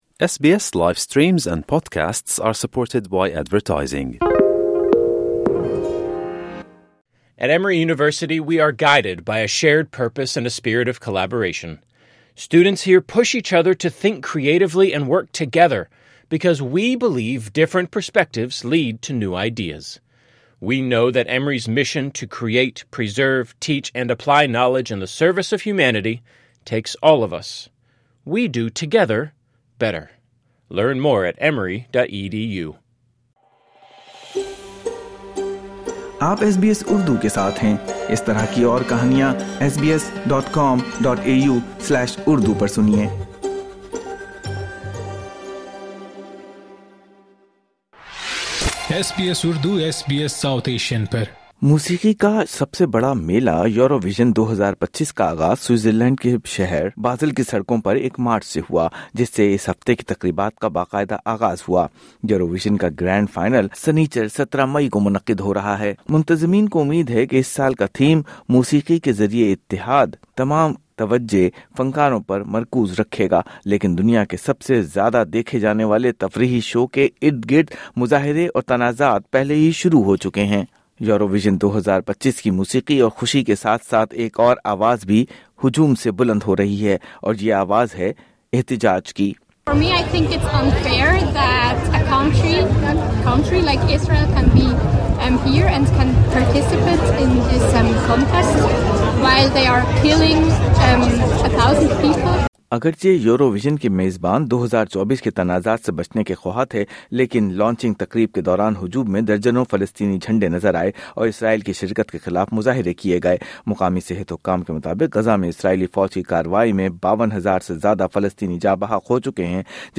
دنیا میں سب سے ذیادہ دیکھا جانے والا موسیقی کا مقابلہ یورو وژن کے منتظمین کی امیدوں کے برخلاف اور اس سال کا تھیم "موسیقی کے ذریعے اتحاد" دئے جانے کے باوجود موسیقی کے گرینڈ تفریحی شو کے اردگرد مظاہرے اور تنازعات پہلے ہی شروع ہو چکے ہیں۔مزید جانئے رپورٹ میں۔